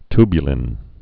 tu·bu·lin
(tbyə-lĭn, ty-)